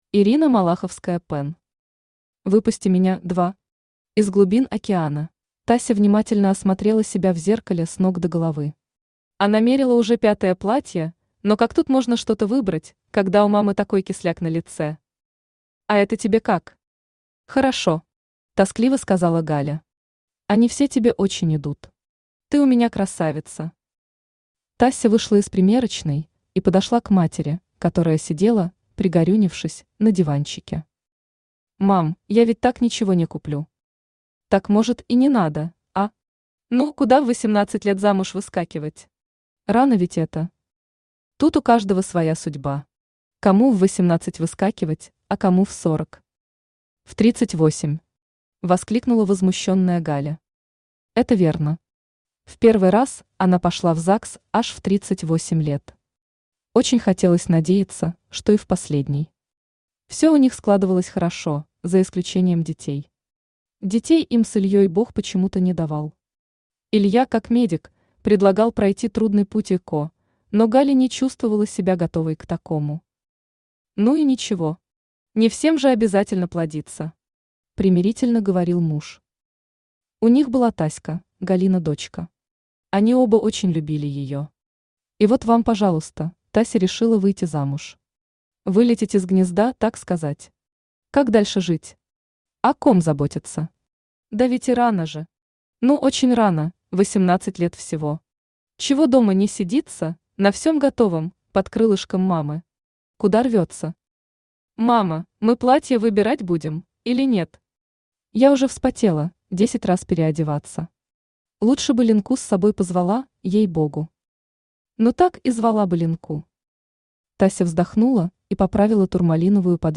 Аудиокнига Выпусти меня – 2. Из глубин океана | Библиотека аудиокниг
Из глубин океана Автор Ирина Малаховская-Пен Читает аудиокнигу Авточтец ЛитРес.